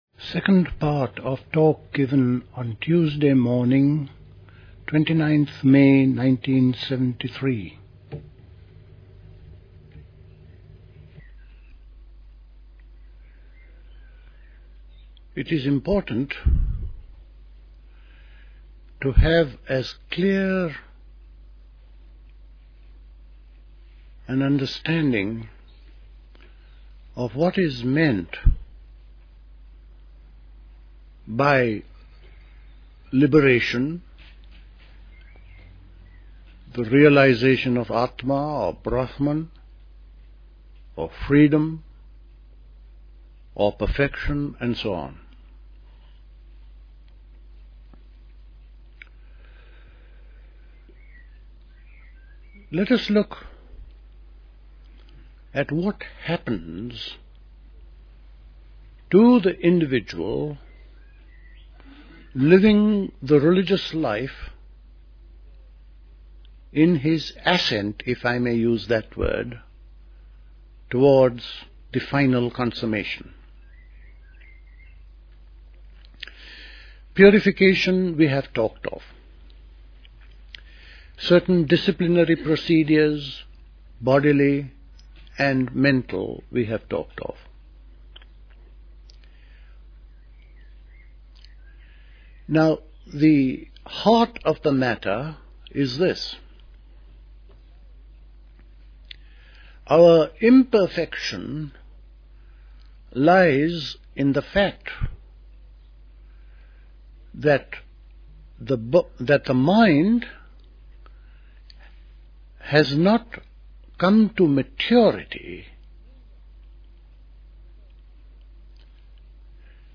Recorded at the 1973 Catherington House Summer School.